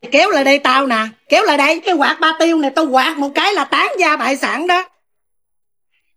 Thể loại: Câu nói Viral Việt Nam